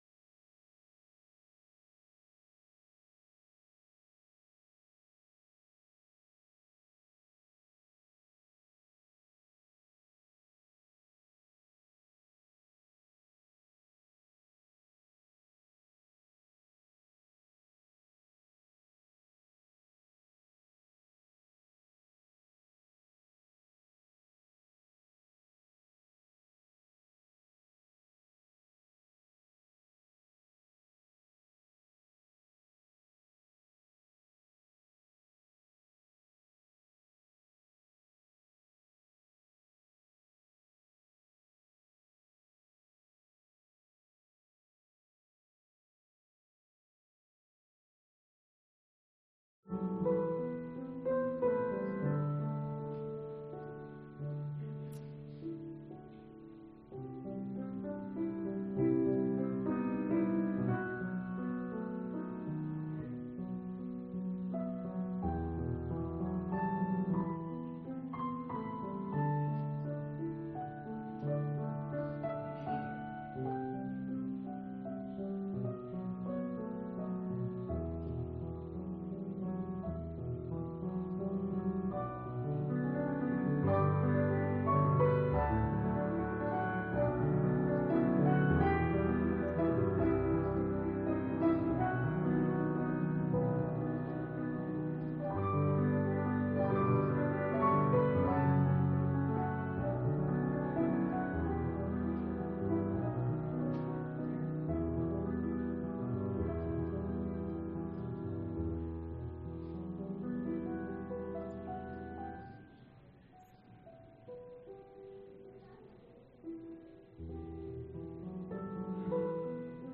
Christmas Eve Candlelight Service 2024